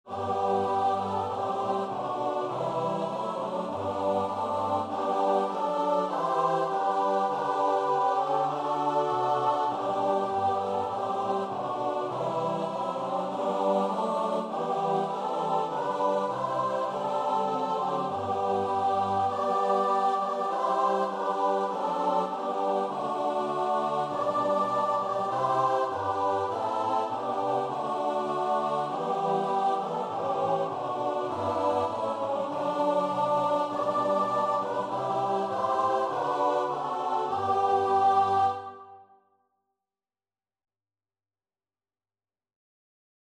Free Sheet music for Choir (SATB)
SopranoAltoTenorBass
G major (Sounding Pitch) (View more G major Music for Choir )
4/4 (View more 4/4 Music)
Classical (View more Classical Choir Music)